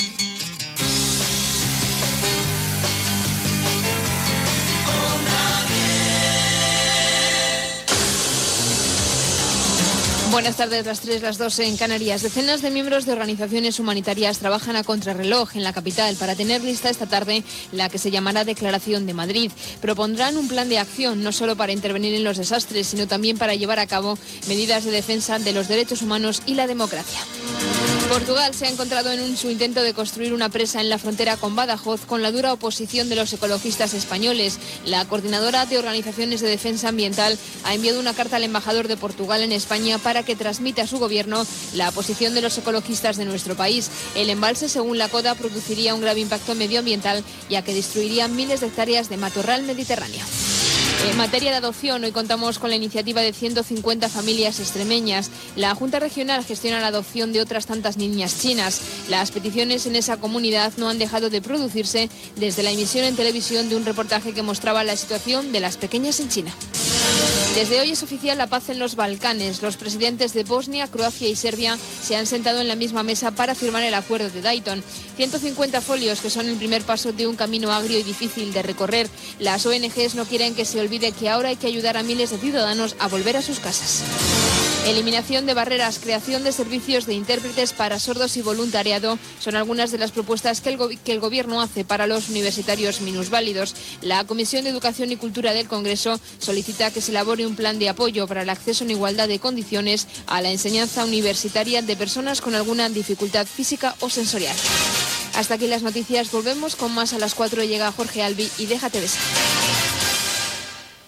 Indicatiu de la ràdio, hora i resmum informatiu: Declaración de Madrid, projecte d'envassament a Portugal, adopcions a Extremadura, signat l'acord de Dayton que posa fi a la Guerra dels Balcans...
Informatiu
FM